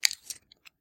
工具 剃须刀 01年
描述：正在处理盒式切割机的声音。 此文件已标准化，大部分背景噪音已删除。没有进行任何其他处理。
Tag: 切割机 缩回 刀片 刀具 延伸 剃须刀